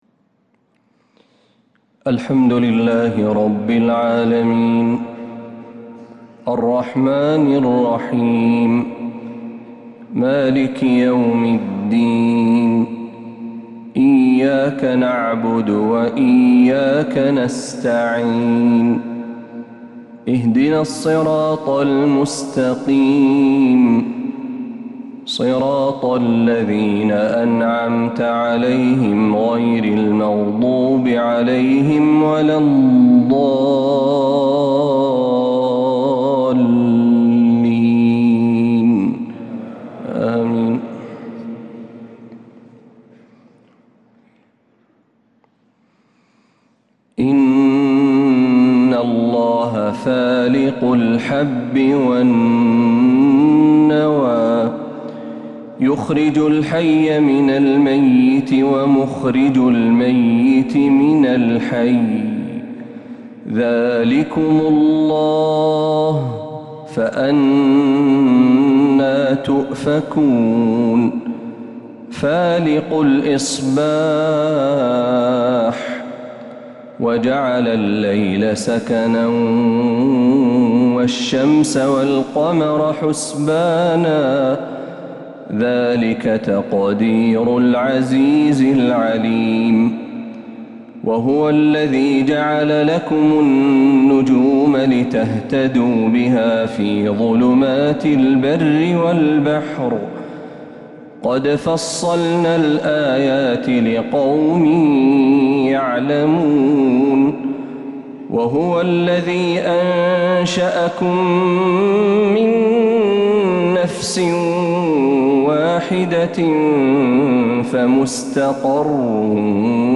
فجر الخميس 8 محرم 1447هـ من سورة الأنعام 95-117 | Fajr prayer from Surat Al-An'aam 3-7-2025 > 1447 🕌 > الفروض - تلاوات الحرمين